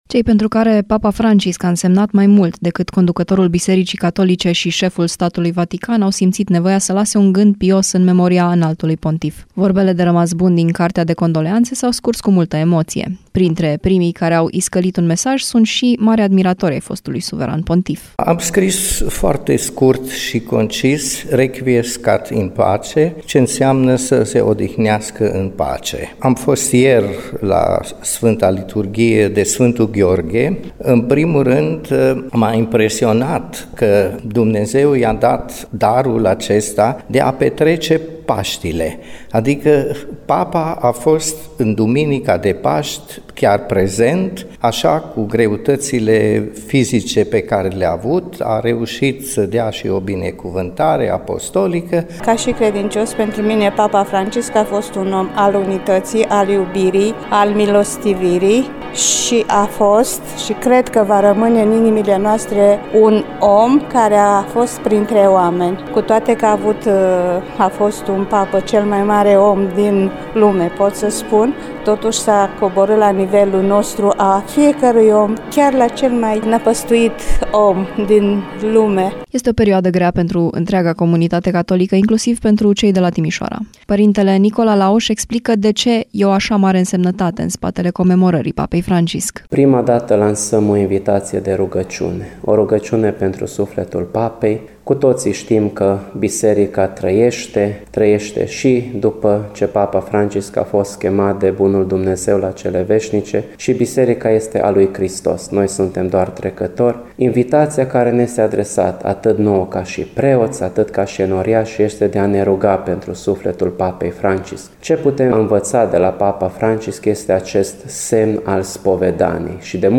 În primul rând, m-a impresionat că Dumnezeu i-a dat darul acesta de a petrece Paștile, adică Papa a fost în Duminica de Paști chiar prezent, așa cu greutățile fizice pe care le-a avut, a reușit să dea și o binecuvântare apostolică”, spune un timișorean.
Cu toate că a fost cel mai mare om din lume, pot să spun, totuși s-a coborât la nivelul nostru a fiecărui om, chiar la cel mai năpăstuit om din lumeni”, spune o timișoreancă.